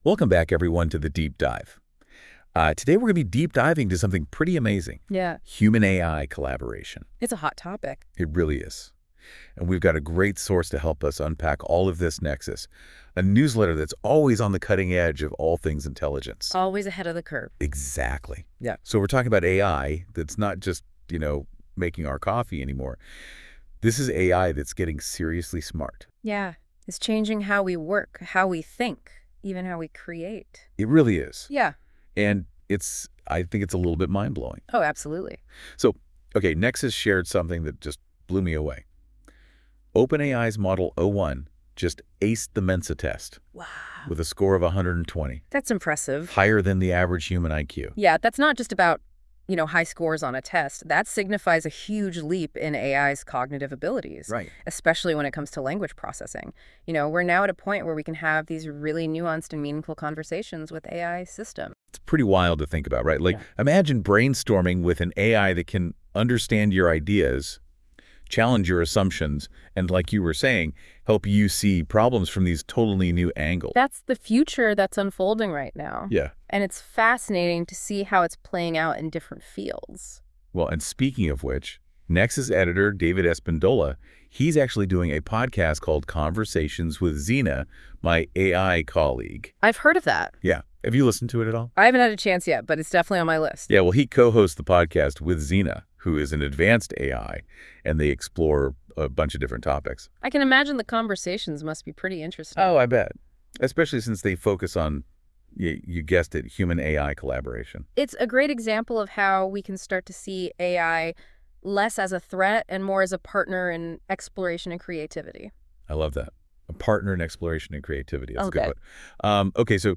Nexus Deep Dive is an AI-generated conversation in podcast style where the hosts talk about the content of each issue of Nexus: Exploring the Frontiers of Intelligence .